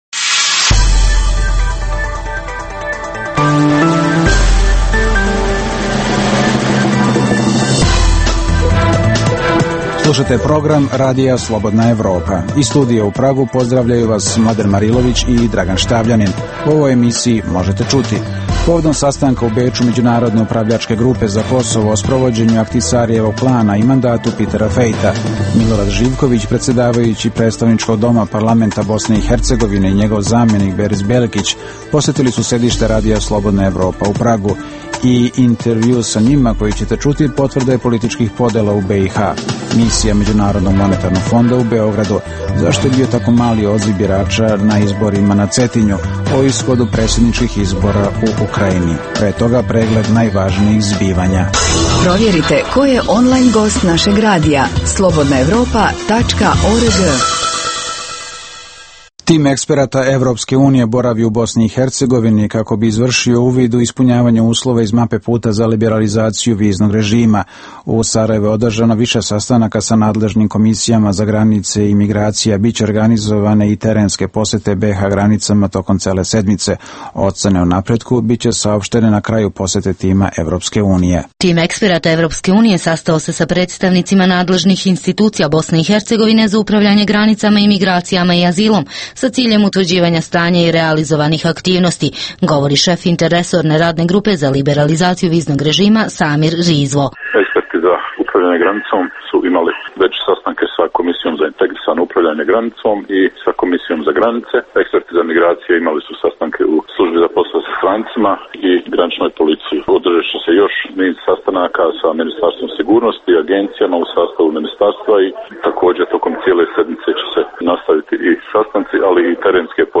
I intervju sa njima koji možete čuti, potvrda je političkih podela u BiH. - Kosovo je dobilo podršku Međunarodne upravljačke grupe u planovima za evropsku integraciju, izjavili su zvaničnici Vlade Kosova posle sastanka ovog tela u Beču.